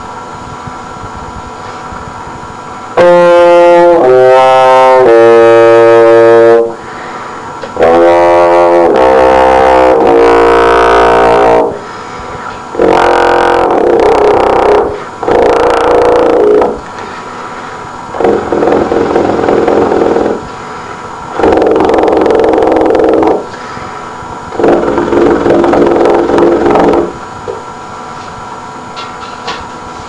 bass trombone playing F, C, Bb in four octaves ending with triple pedal Bb.
TriplePedal.mp3